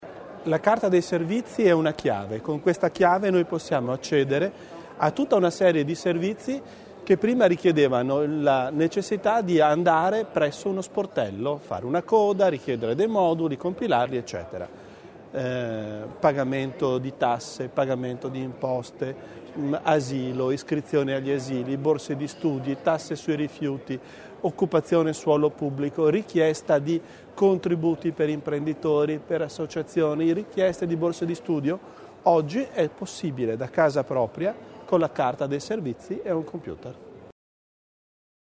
L'Assessore Bizzo sulle novità per la Carta Provinciale dei Servizi